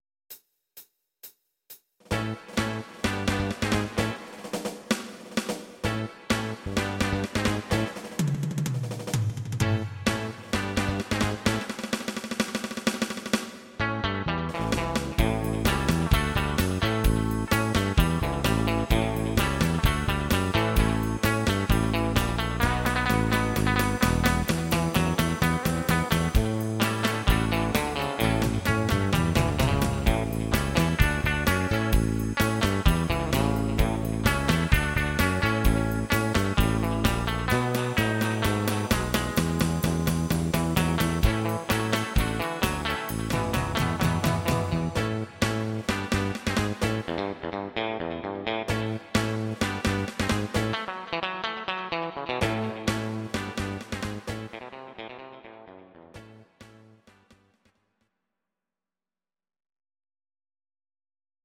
These are MP3 versions of our MIDI file catalogue.
Please note: no vocals and no karaoke included.
instr. Gitarre